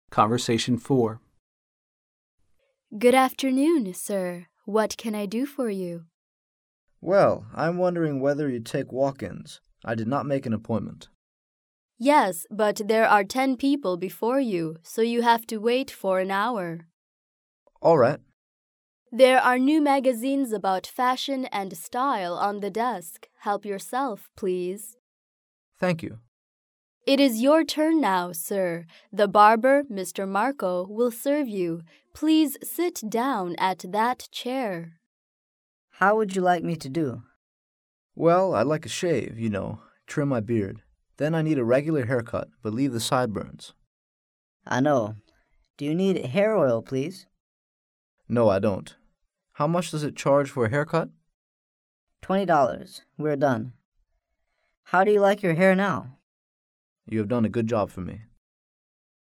Conversation 4